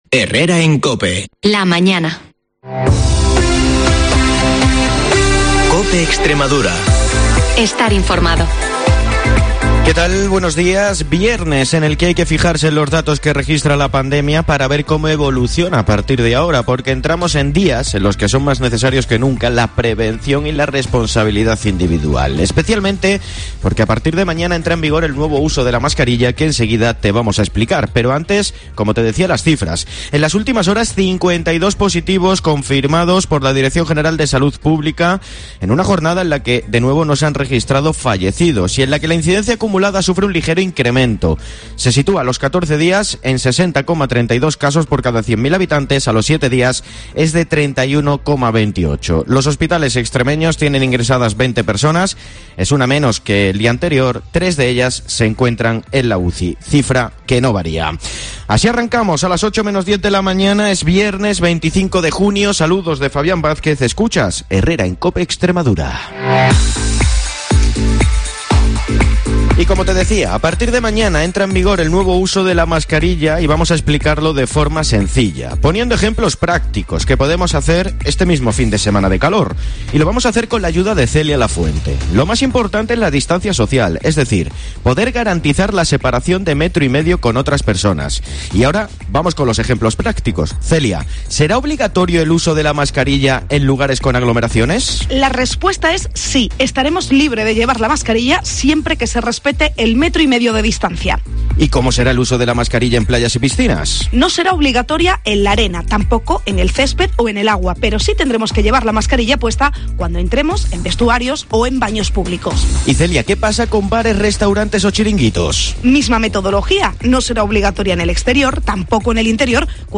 el informativo líder de la radio en la región